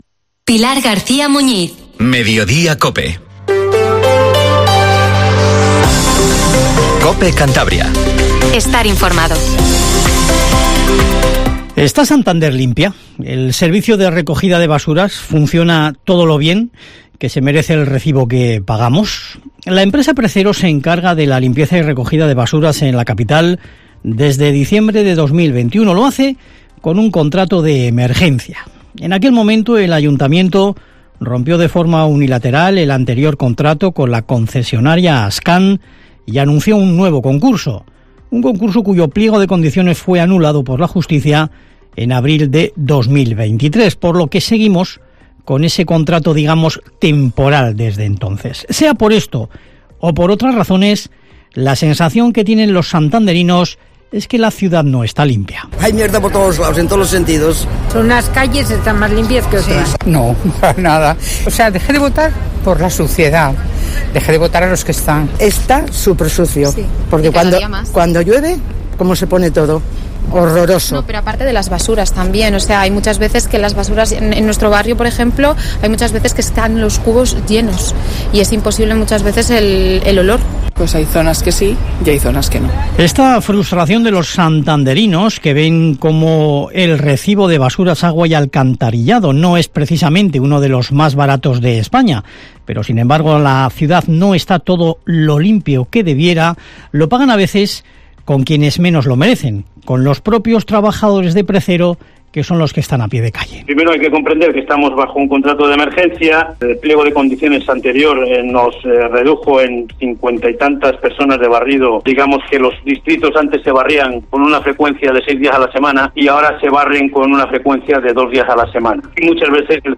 Informativo MEDIODIA en COPE CANTABRIA 14:20